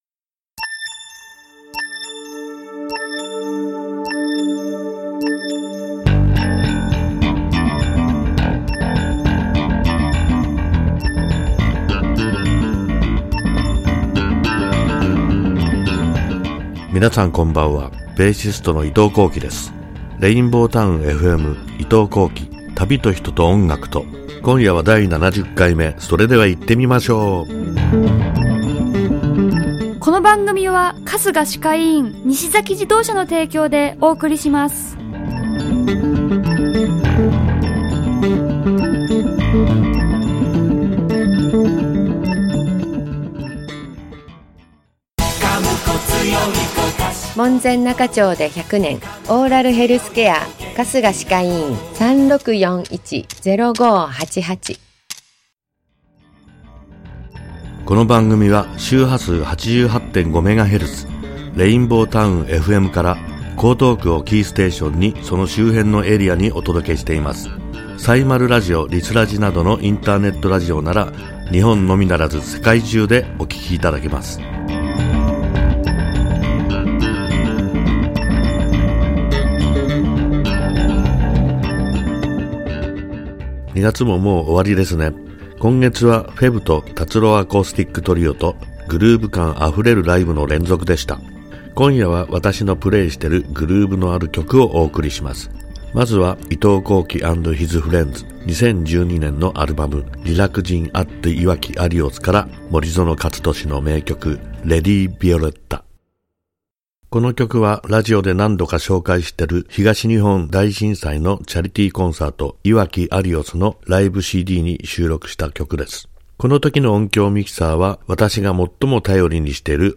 ※アーカイブ・オンデマンドでは、トーク内容のみで楽曲はかけておりません。